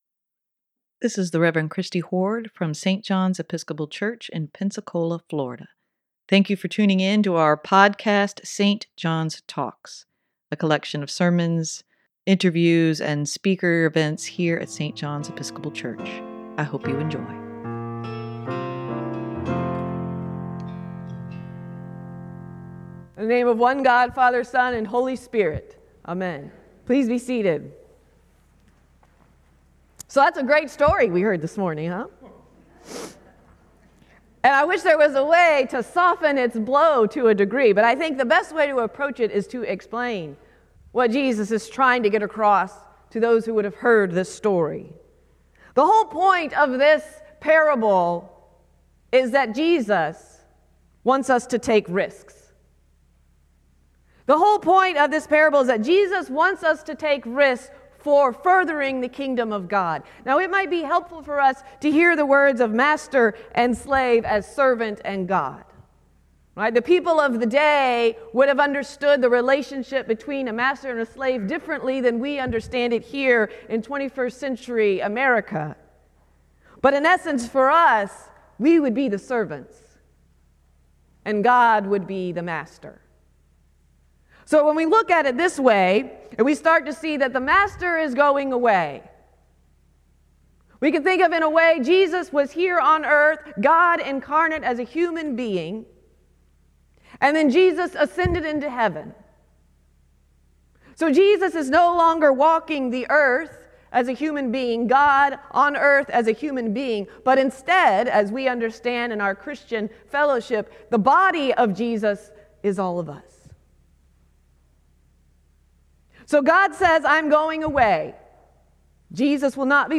Sermon for Nov. 19, 2023: Use your gifts for the glory of God